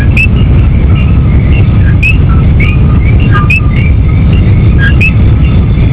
El Coqu� de la Monta�a es la �nica otra especie de Puerto Rico que cuando canta dice CO-QUI, pero lo dice mucho m�s r�pido que el Coqu� Com�n. Se distingue f�cilmente por su voz y porque la parte superior del ojo es blanca.